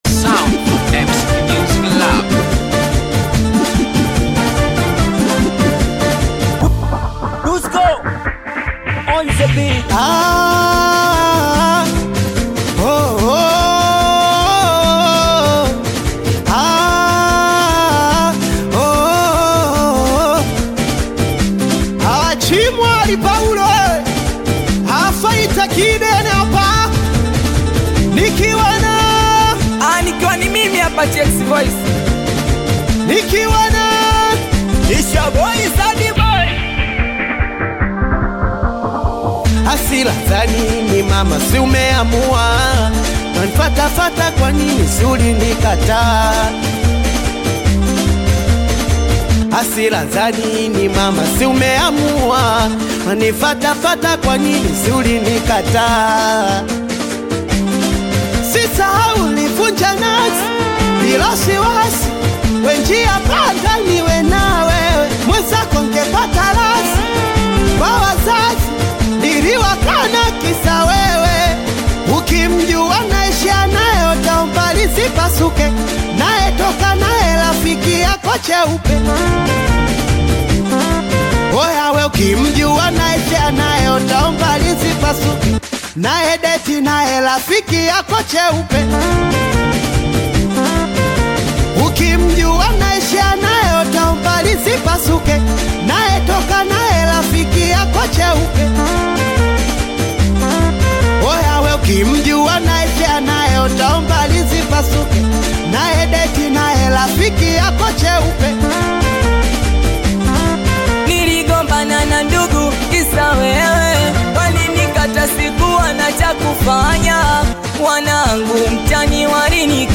Tanzanian Bongo Flava Singeli
Singeli You may also like